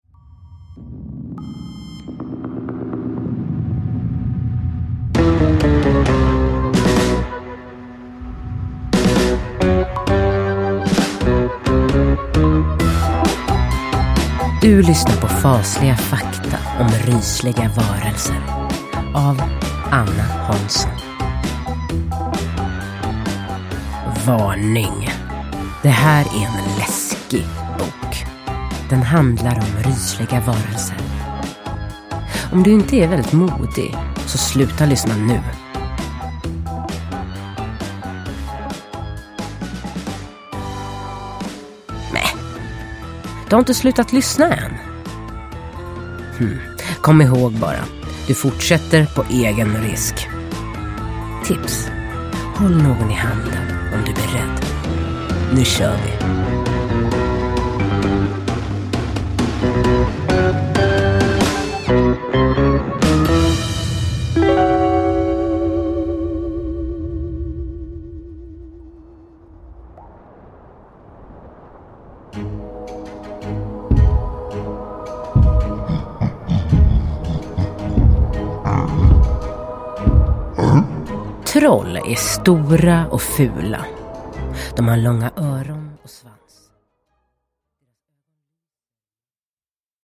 Fasliga fakta om rysliga varelser – Ljudbok – Laddas ner